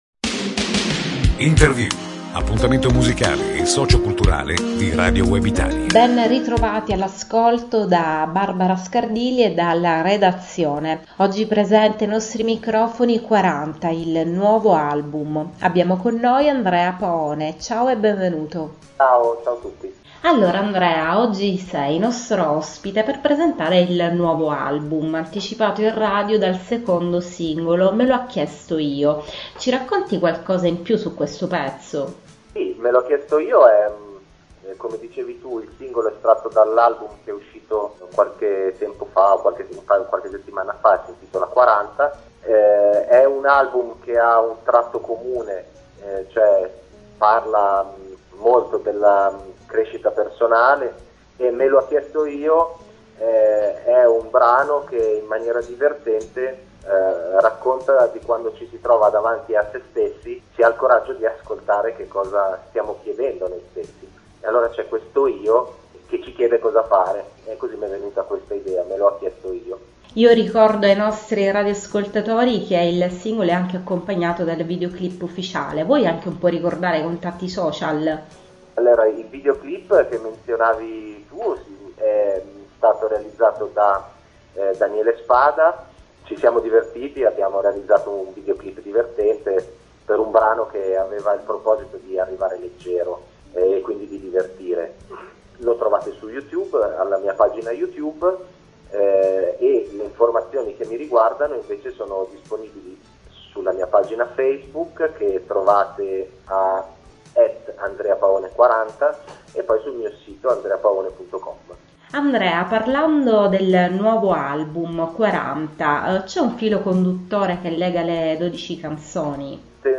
l'intervista per "40", il nuovo album